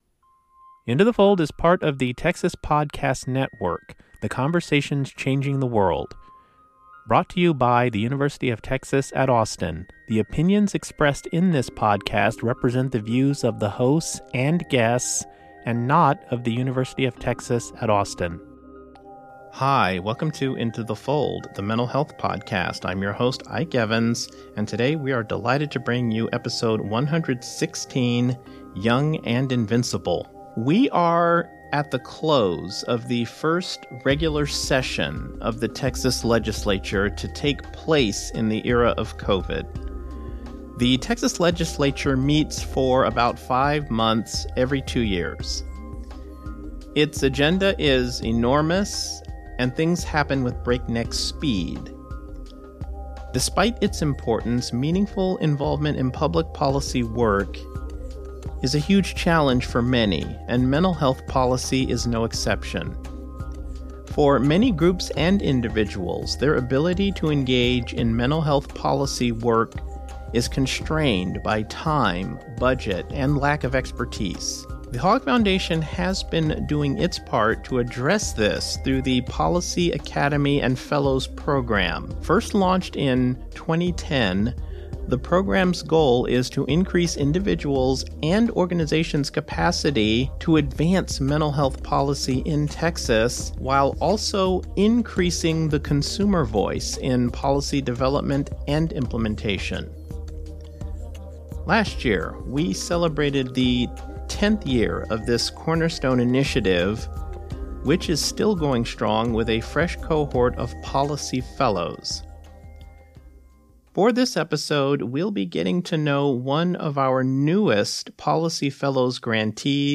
At the close of the first session of the Texas legislature to take place during the time of COVID, which had an enormous agenda to cover at breakneck speed, one thing is abundantly clear: meaningful engagement with public policy is challenging work. On this episode of the podcast, we sit down with three people dedicated to amplifying the voices of youth and young adults to advance mental health policy work in Texas.